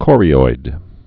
(kôrē-oid)